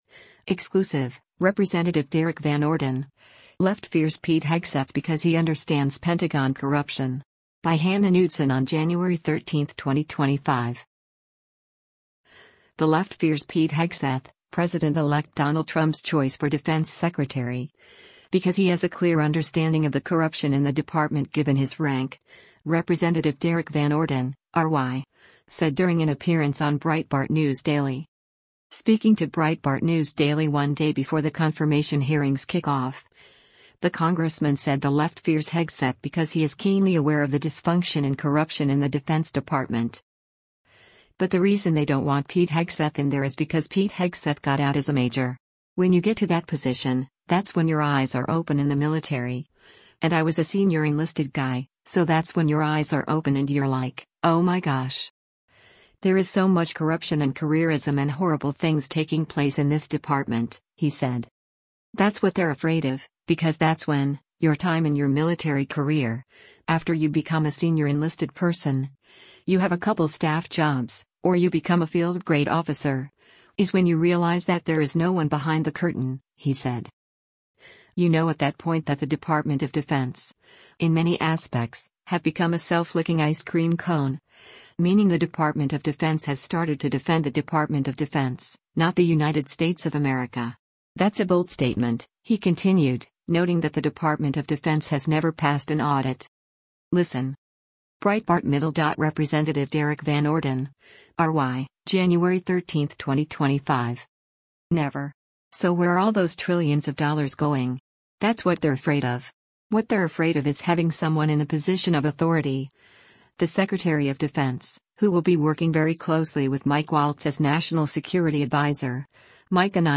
The left fears Pete Hegseth, President-elect Donald Trump’s choice for Defense Secretary, because he has a clear understanding of the corruption in the department given his rank, Rep. Derrick Van Orden (R-WI) said during an appearance on Breitbart News Daily.
Breitbart News Daily airs on SiriusXM Patriot 125 from 6:00 a.m. to 9:00 a.m. Eastern.